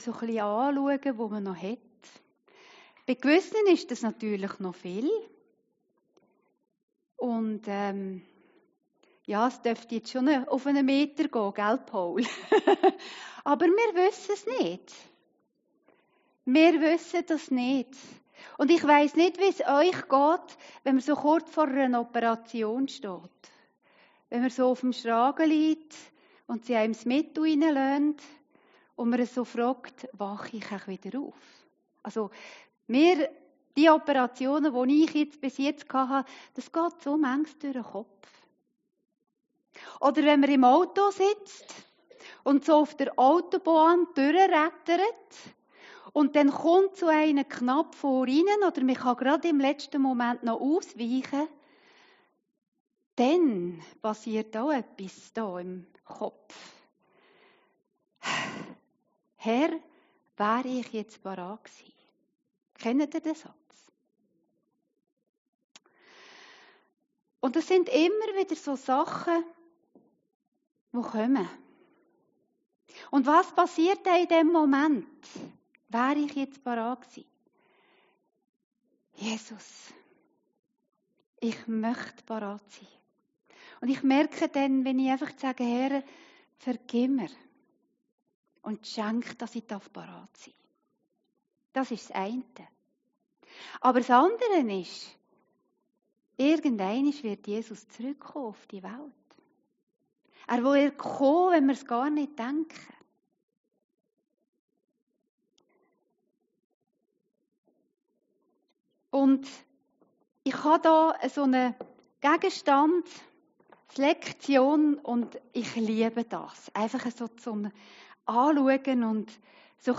Predigten Heilsarmee Aargau Süd – FOKUSSIERT AUF DIE EWIGKEIT